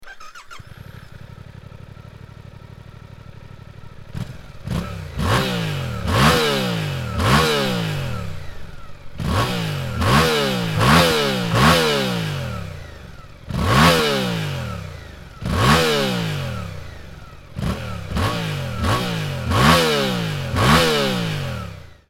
775ccの直列2気筒エンジンで
かなり静かな排気音なので
空吹かしの排気音を収録してきたのでどうぞ･･
GSX-8Rの排気音